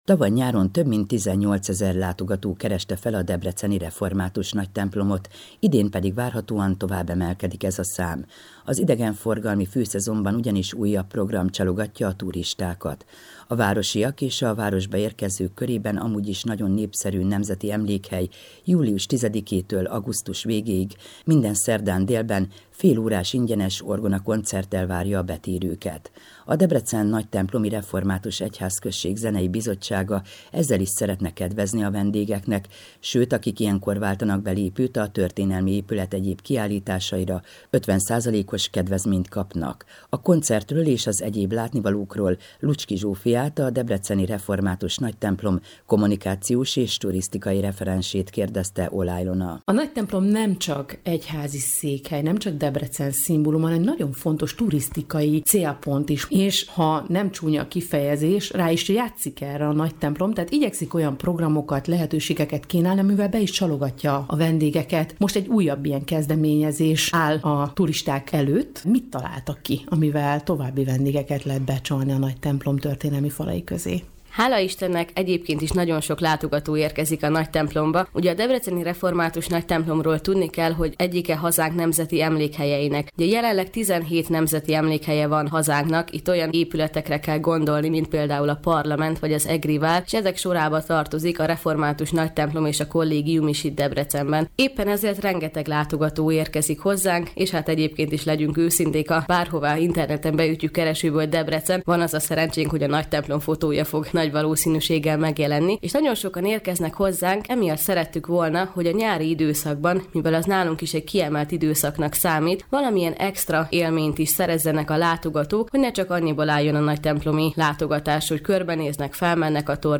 Az Európa Rádióban